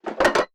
SFX_PickUp_03.wav